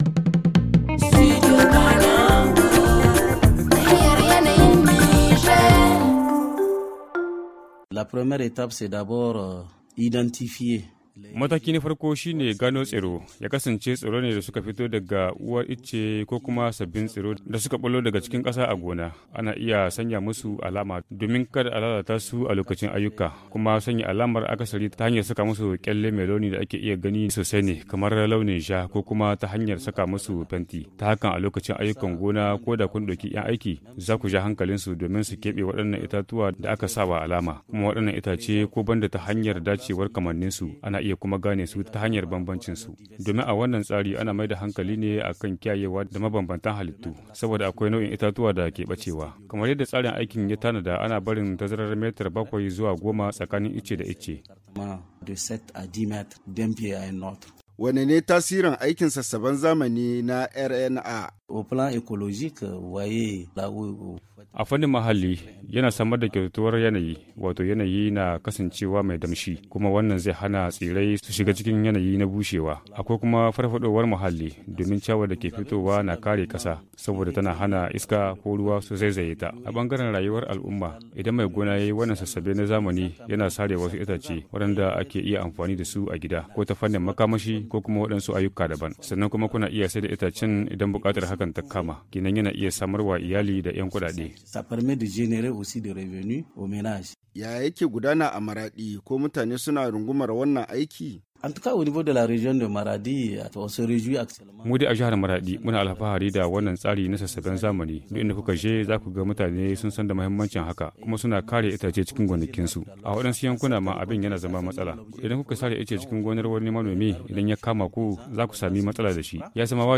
Le magazine en haoussa